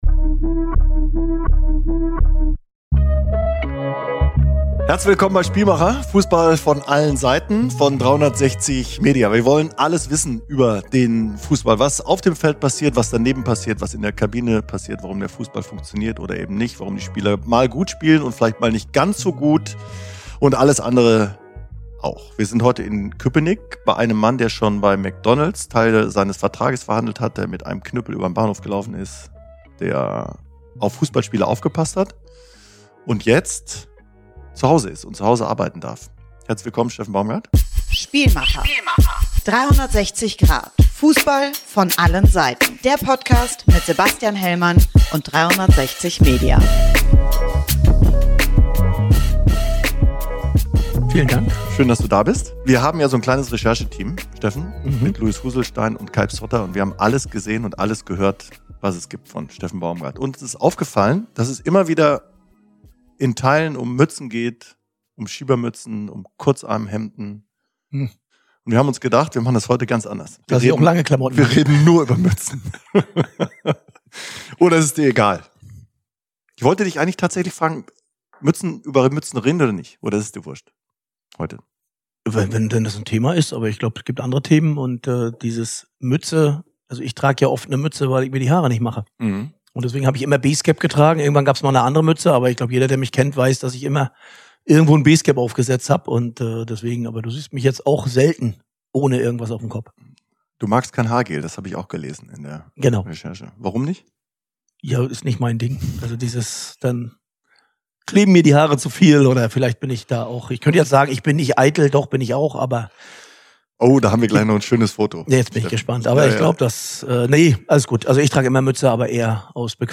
Baumgart erzählt Sebastian Hellmann von seinem bewegenden Weg in den Fußball, spricht über Florian Wirtz und Jamal Musiala - sowie die Pflege seines Instagram-Kanals. Er verrät auch, warum es ihn glücklich macht, dass er häufiger unterschätzt wird und warum er Fußballspiele mittlerweile ohne Kommentar schaut.